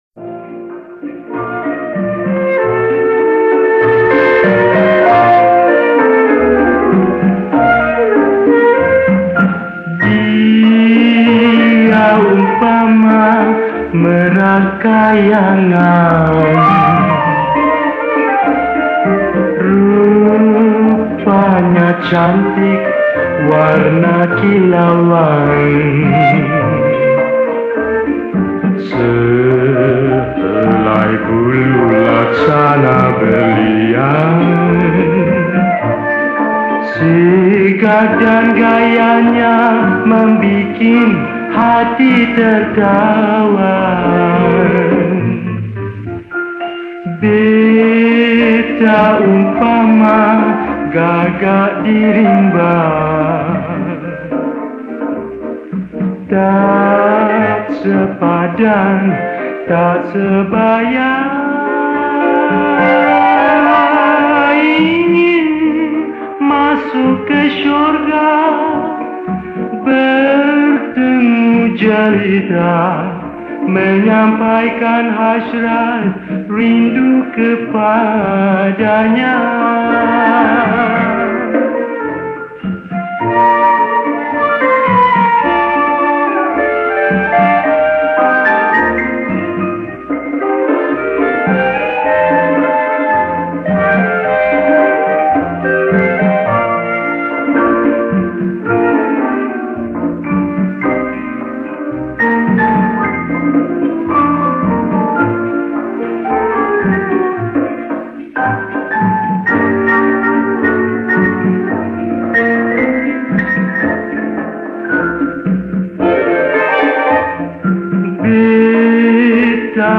Malay Songs
Malay Song